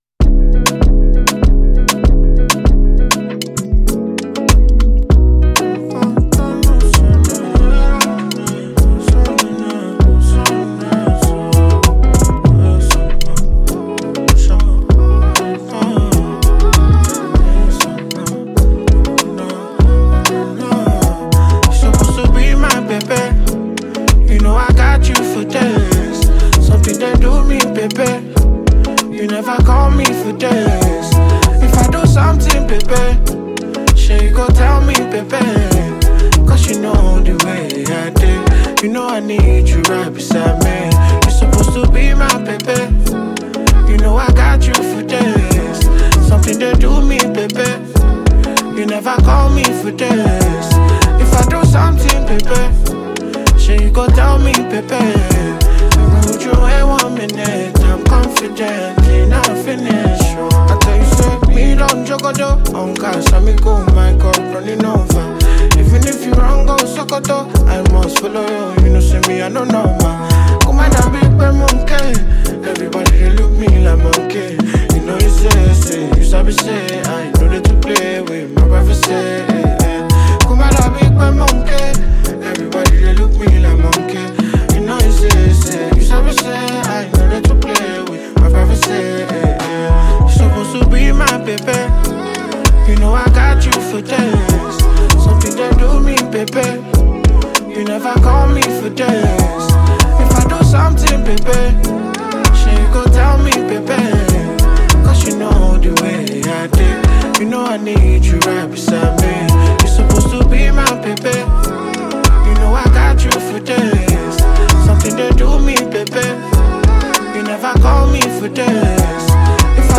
unique visceral vocals